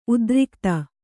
♪ udrikta